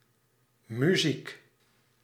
Ääntäminen
UK : IPA : /ˈmjuːzɪk/ US : IPA : /ˈmjuzɪk/